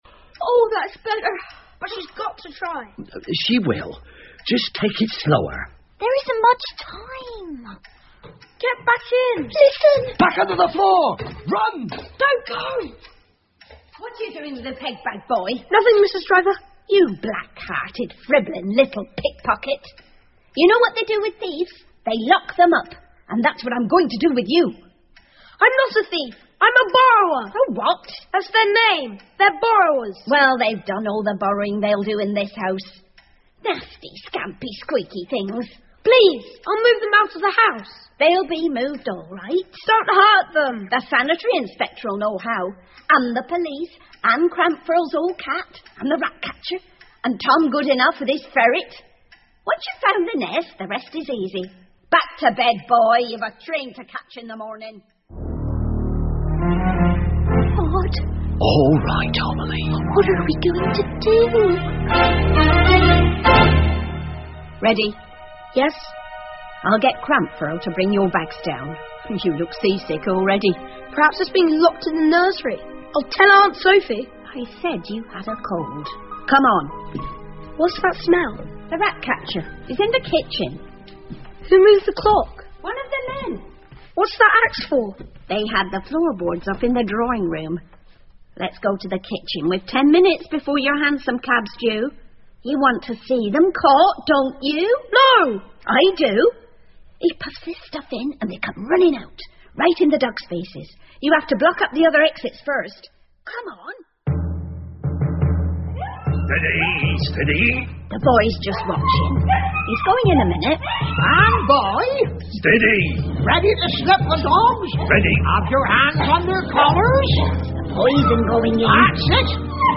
借东西的小人 The Borrowers 儿童广播剧 9 听力文件下载—在线英语听力室